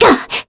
Scarlet-Vox_Attack3_kr.wav